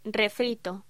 Título Locución: Refrito
Sonidos: Voz humana